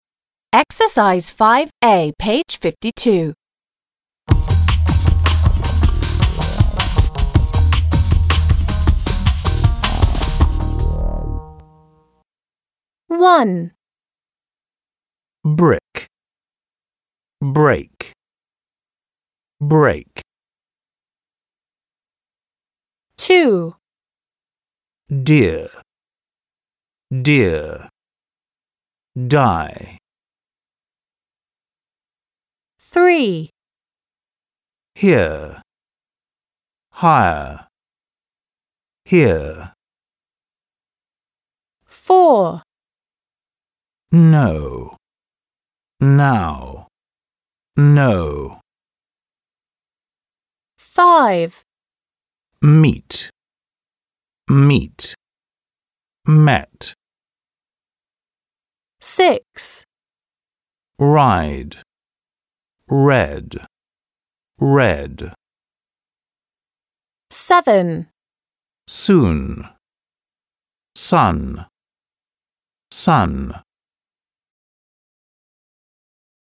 5. a) Listen and circle the word that does not sound the same as the others. Listen again and repeat. − Послушай и обведи слова, которые звучат не так же как остальные. Послушай снова и повтори.